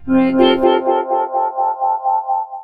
READY VOC.wav